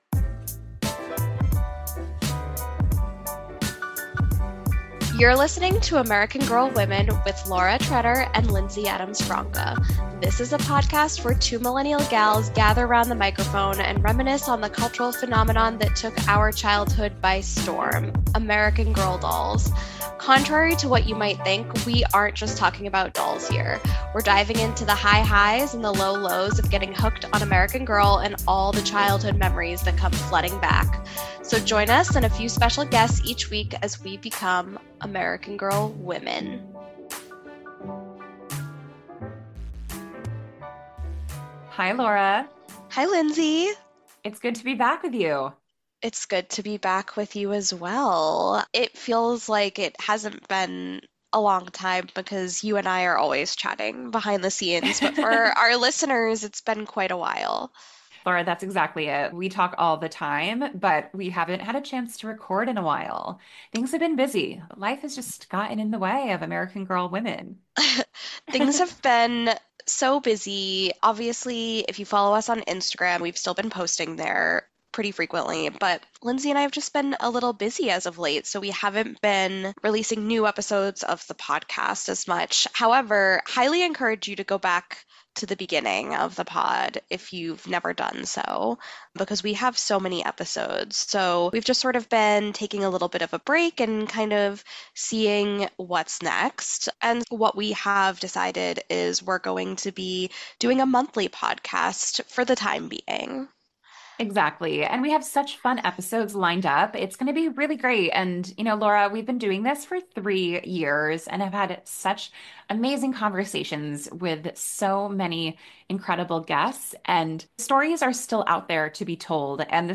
American Girl Women is a podcast where two millennial gals gather ‘round the microphone and reminisce on the cultural phenomenon that took our childhoods by storm: American Girl Dolls.